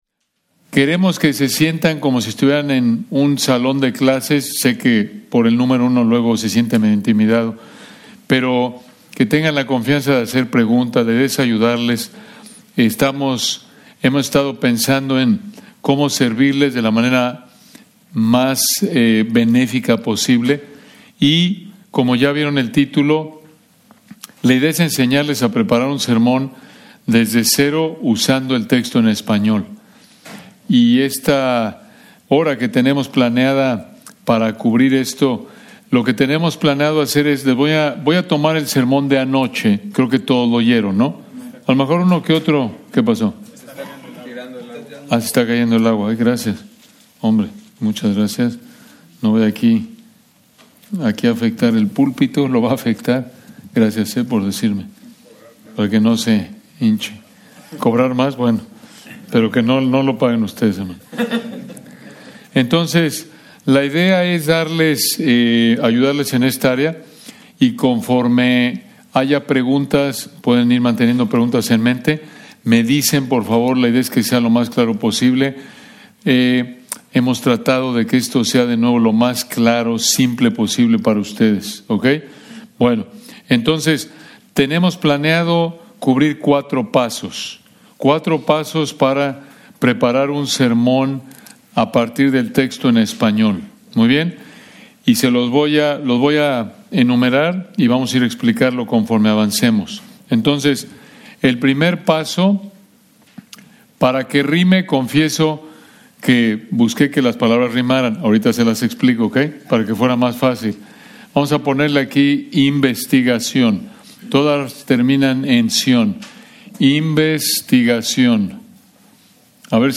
Taller: Preparar un sermón desde el español (Spanish)